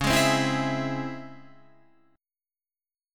DbmM7 chord